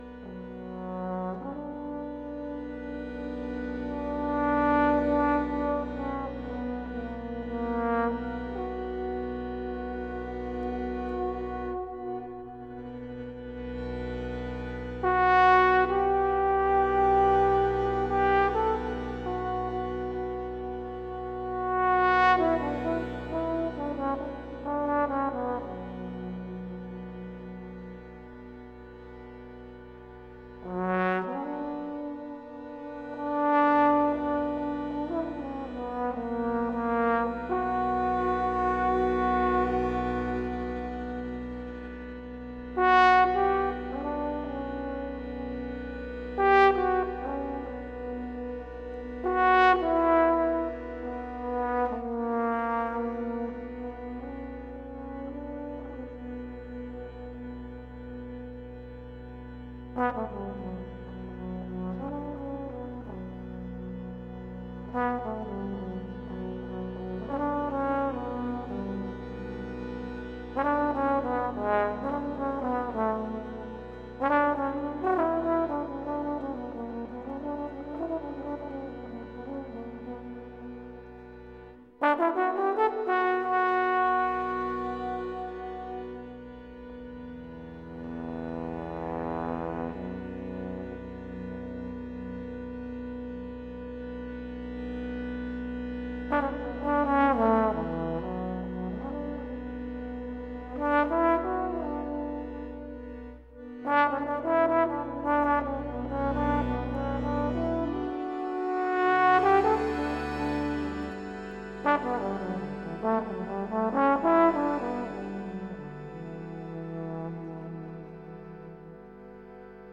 With bass trumpet.